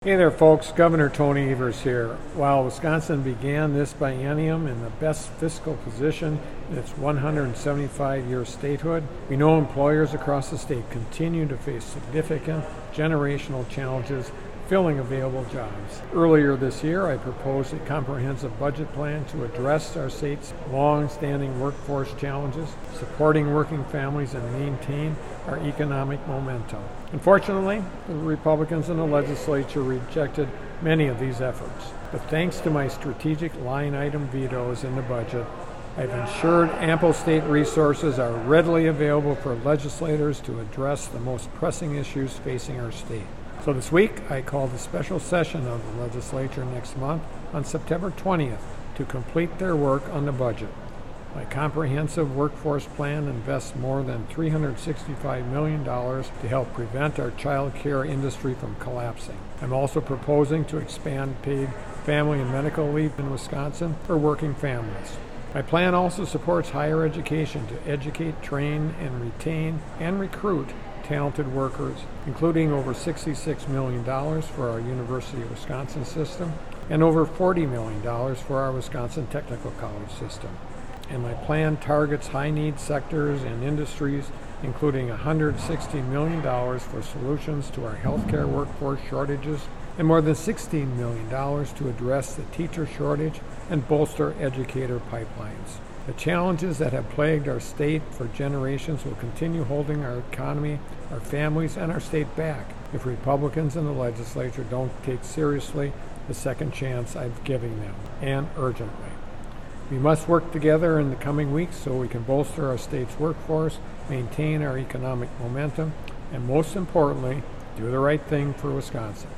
Weekly Dem radio address: Gov. Evers on special session to address state’s chronic workforce challenges - WisPolitics
MADISON — Gov. Tony Evers today delivered the Democratic Radio Address on his announcement this week, calling a special session of the Wisconsin State Legislature at 12 p.m. on Wed., Sept. 20, 2023, to complete their work on the 2023-25 biennial budget and pass a meaningful, comprehensive plan to address the state’s longstanding, generational workforce challenges.